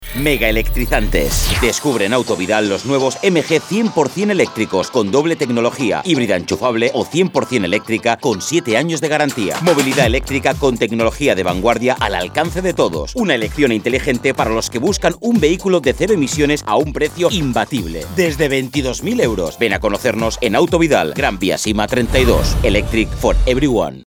cuna-radio-1-Sl-E5J7u.mp3